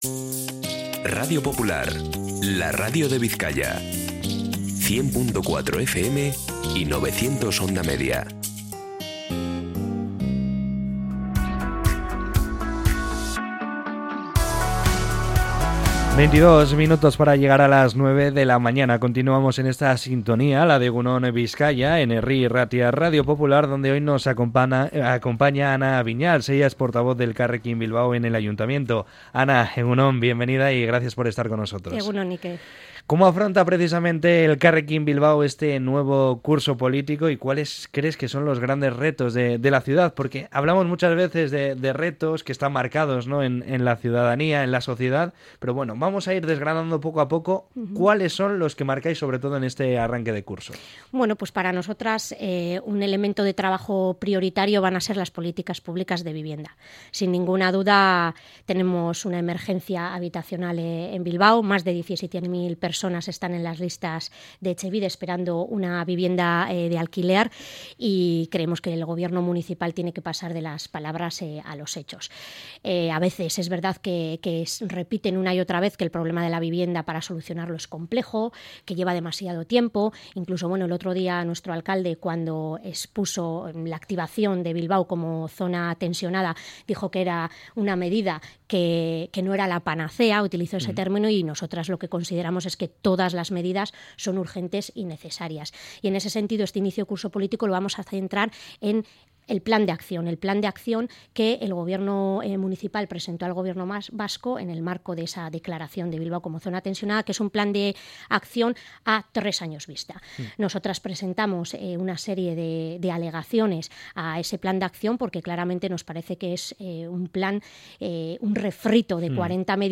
Entrevista con Ana Viñals, portavoz de Elkarrekin Bilbao en el Ayuntamiento de Bilbao